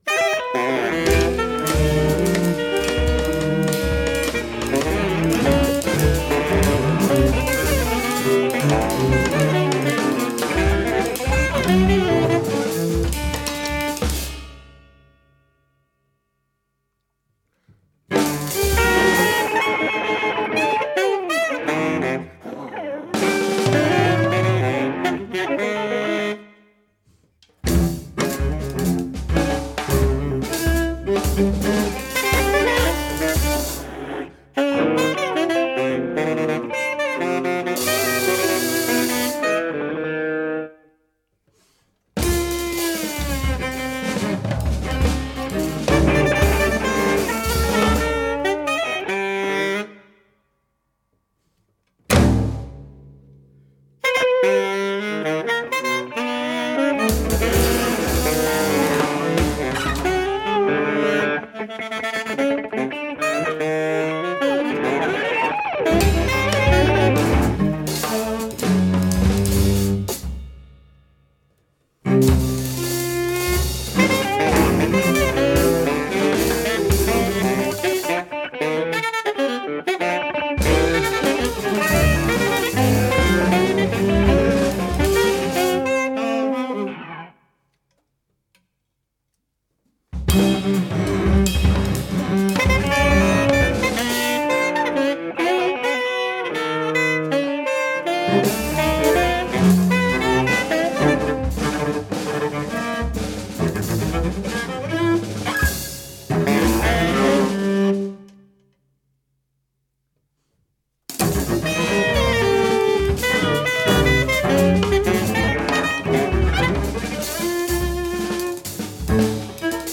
electric guitar
tenor saxophone
cello
percussion
Recorded in Cologne at Topaz Studios
this is instrumental music.